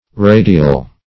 Radial \Ra"di*al\ (r[=a]"d[i^]*al), a. [Cf. F. radial.